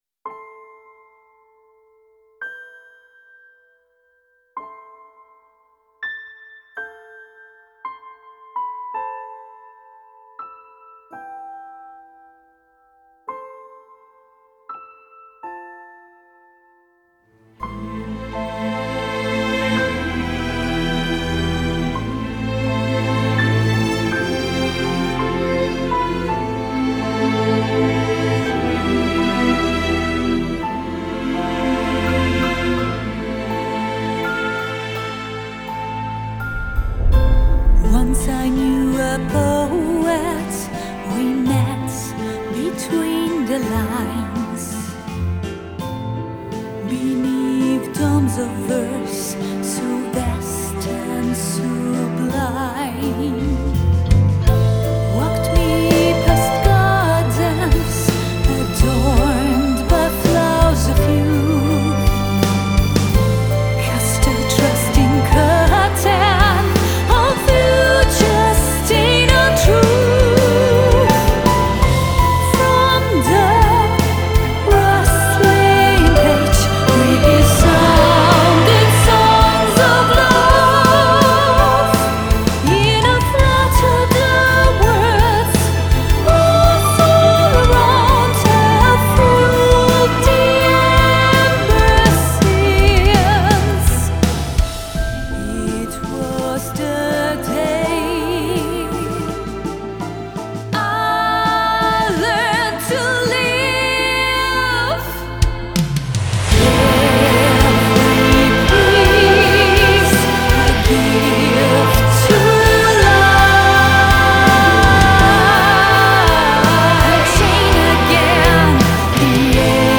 Gothic Metal